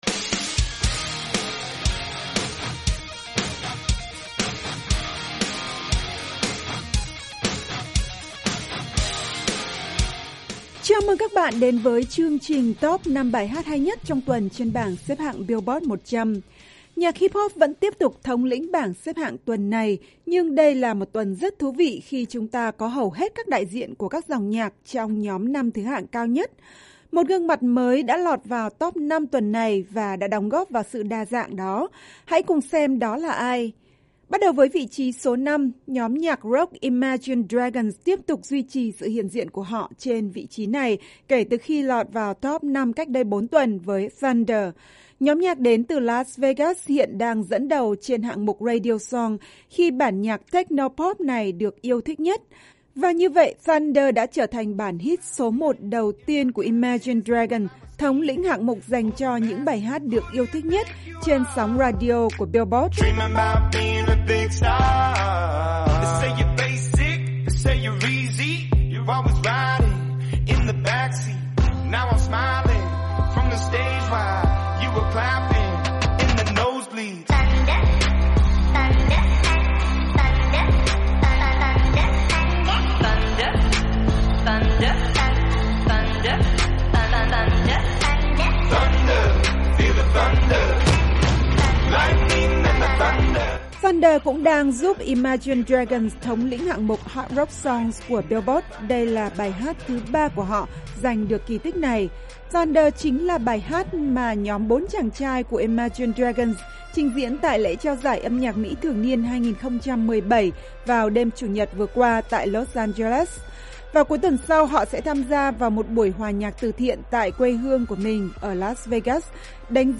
Camila Cabello đang chiếm lĩnh vị trí á quân với 1 bản hit Latin pop quyến rũ.